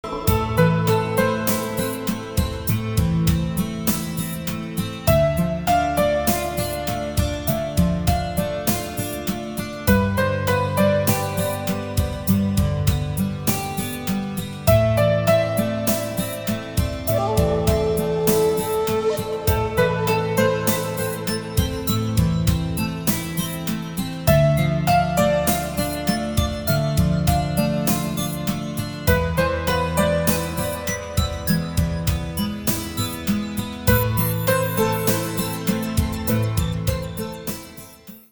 Ringtones Category: Instrumental